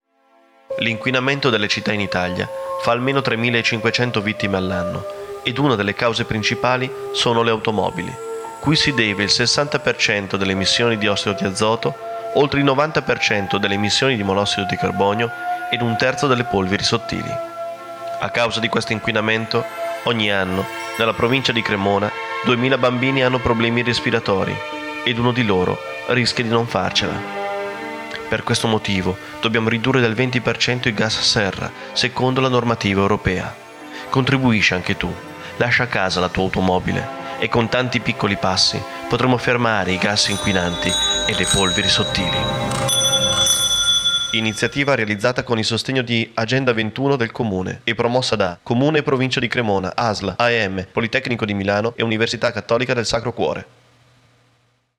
Versione per la radio (wave - 10 Mb )
polveri_radio.wav